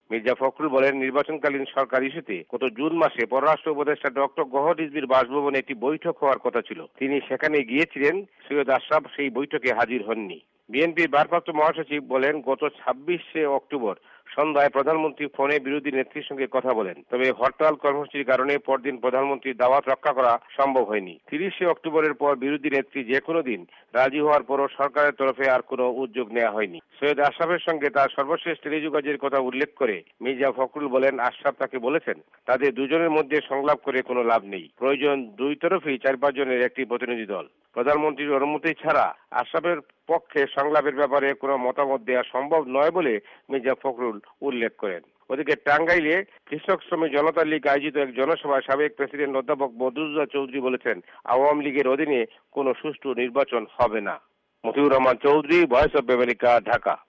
বাংলাদেশে আত্মগোপনে থাকা অবস্থায় অজ্ঞাত স্থান থেকে টেলিফোনে বিএনপি’র ভারপ্রাপ্ত মহাসচিব মির্জা ফখরুল ইসলাম এই অভিযোগ করেন যে চলমান রাজনৈতিক সংকট অবসানে সংলাপের ব্যাপারে সরকার আগ্রহী নয়। তিনি বলেন যে আওয়ামি লীগের সাধারণ সম্পাদক সৈয়দ আশরাফুল ইসলাম তাঁর ফোন ধরছেন না।